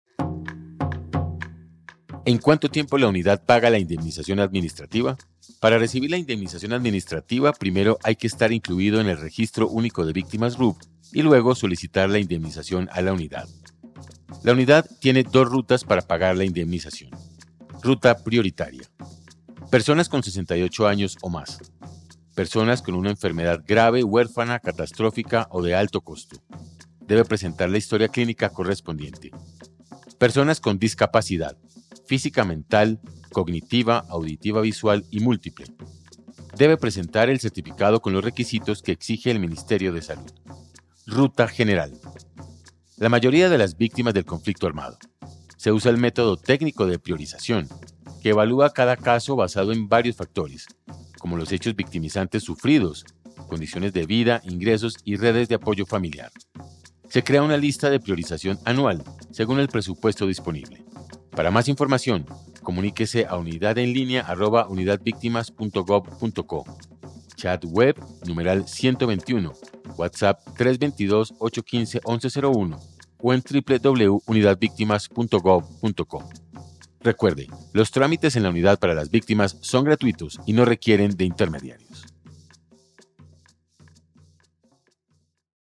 Radio
Cuna-radio-Tiempos-de-indemnizacion-administrativa.mp3